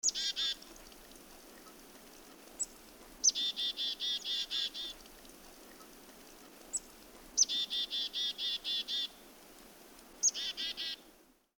tweeting-chickadee.mp3